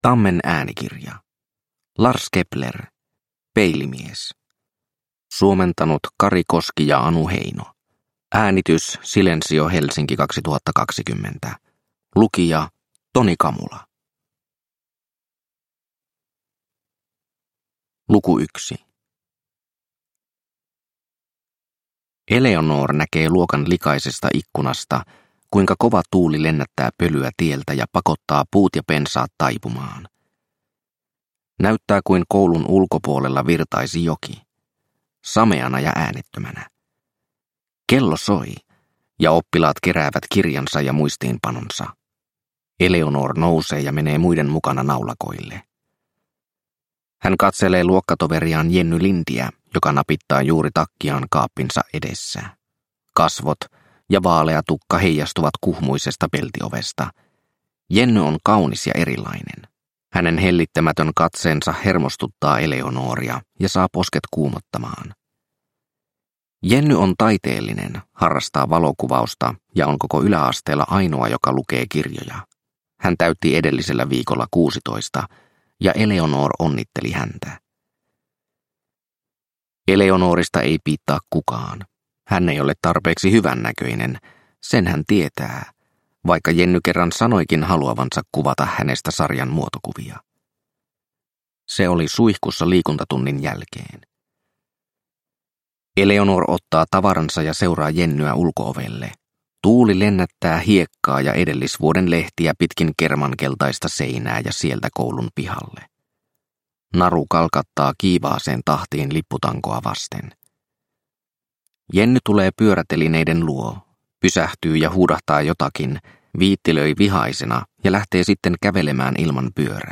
Peilimies – Ljudbok – Laddas ner